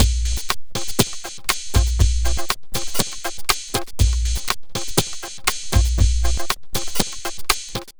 Astro 5 Drumz.wav